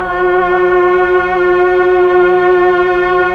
Index of /90_sSampleCDs/Roland LCDP09 Keys of the 60s and 70s 1/PAD_Melo.Str+Vox/PAD_Tron Str+Vox